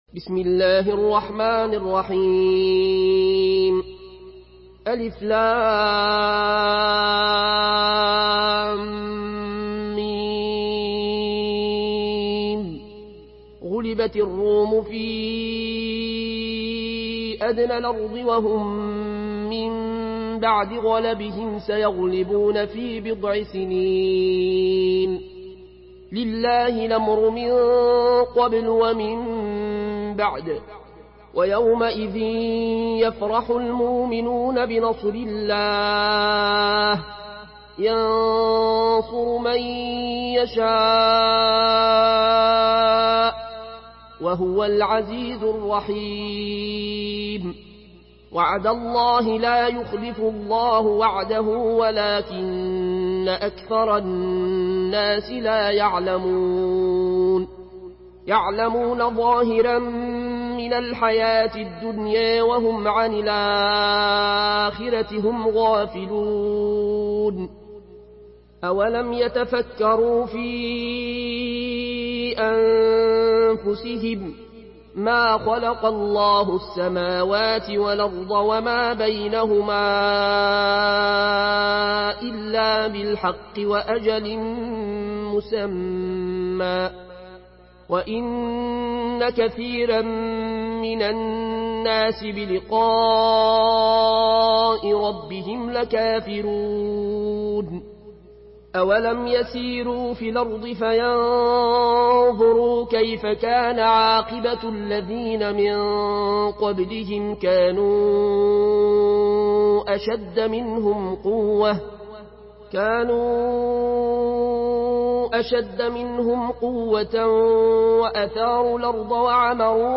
Surah আর-রূম MP3 in the Voice of Al Ayoune Al Koshi in Warsh Narration
Murattal Warsh An Nafi From Al-Azraq way